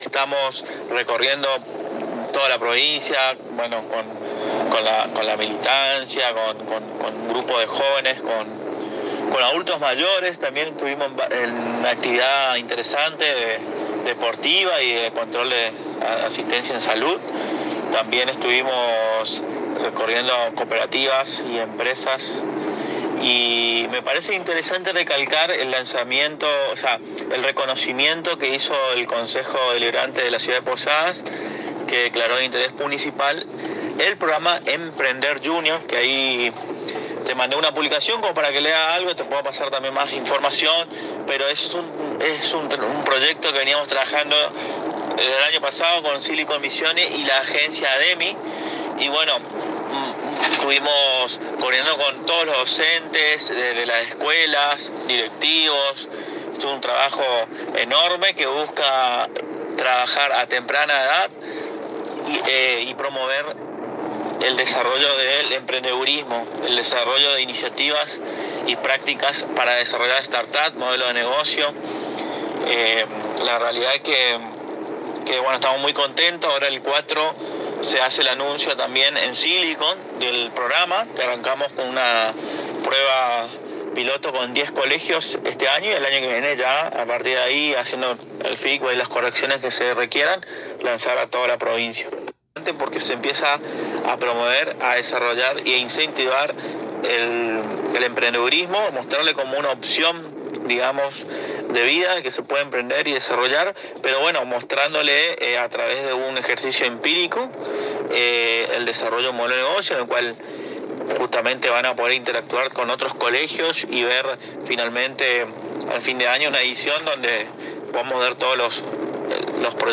en diálogo exclusivo con la ANG